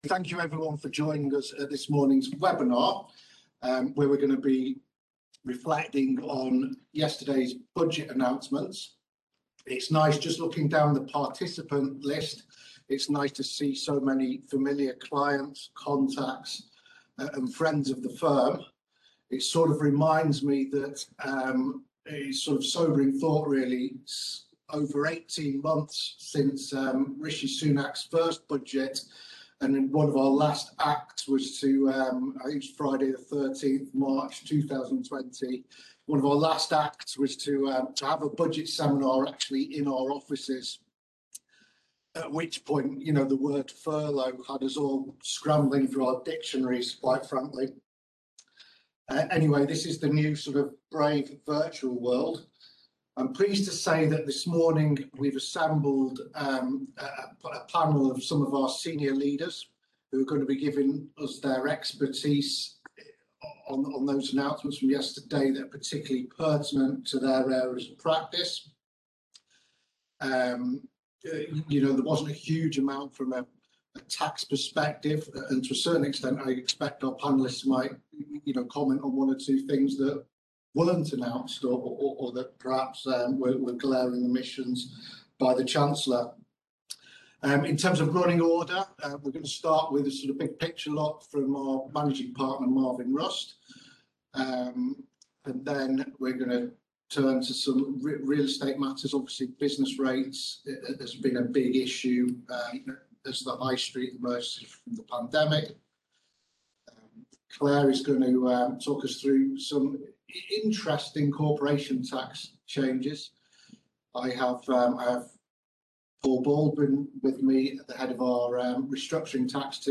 Views on the Autumn Budget and Spending Review 2021 Live Webinar
Alvarez & Marsal Taxand leaders hosted a webinar to discuss the 2021 Autumn Budget and Spending Review released last week, breaking down the key measures announced by the UK government and outlining risks and opportunities for businesses and clients.